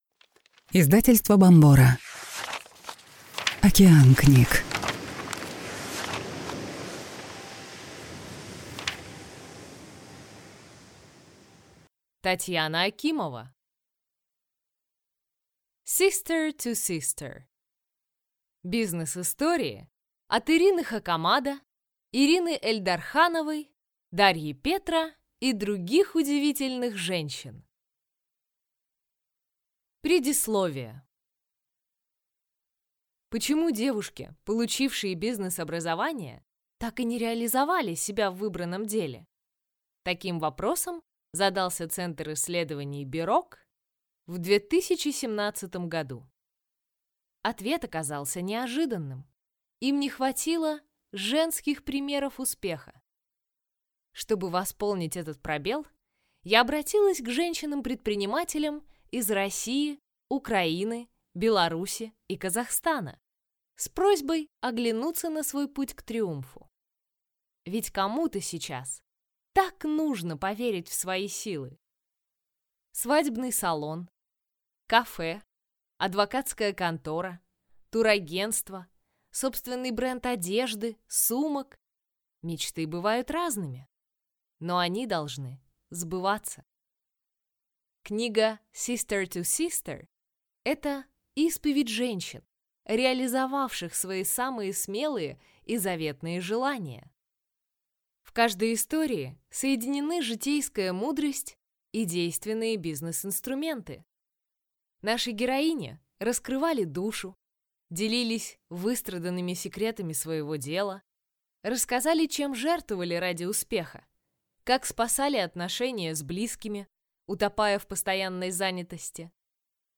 Аудиокнига Sister to sister. Бизнес-истории от Ирины Хакамада, Ирины Эльдархановой, Дарьи Петра и других удивительных женщин | Библиотека аудиокниг